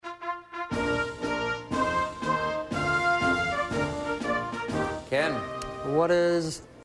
NATIONAL ANTHEMS
The anthem heard